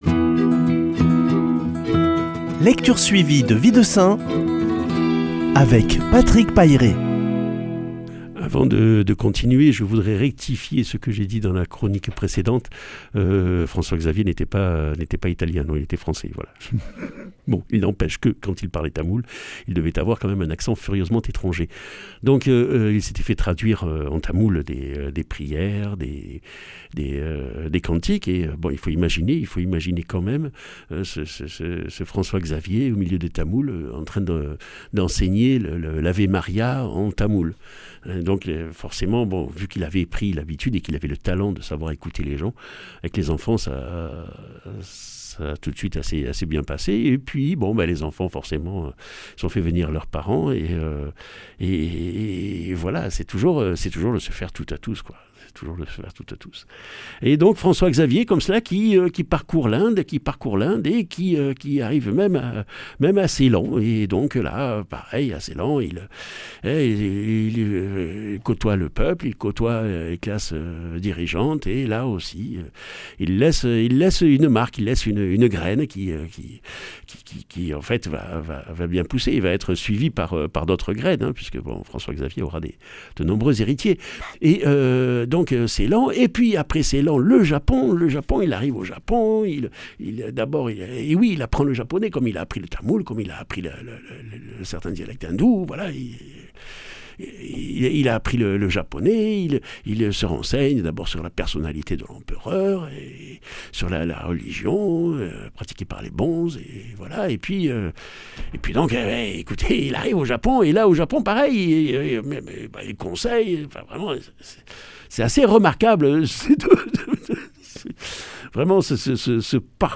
[ Rediffusion ] Arpentant l’extrême orient des Indes au Japon en passant par Ceylan, écoutant, découvrant, comprenant, conseillant, évangélisant.
Lecture suivie de la vie des saints